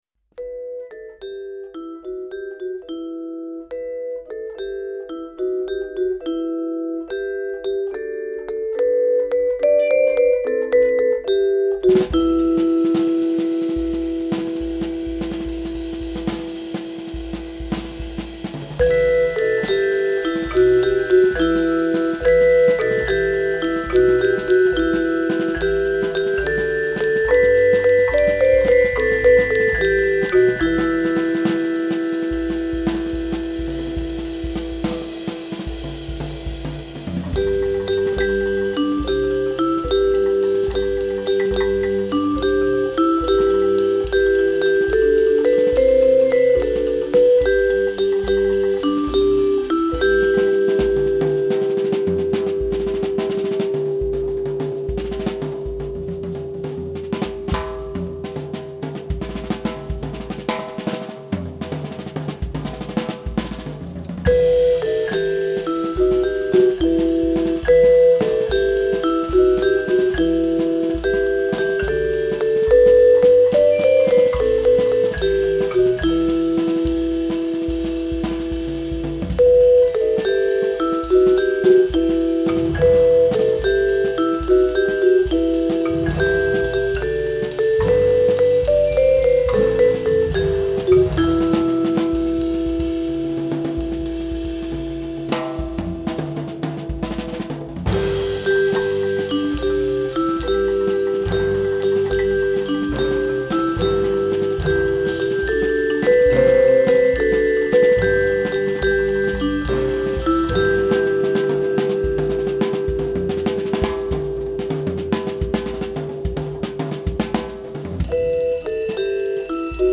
Christmas Songs on Percussion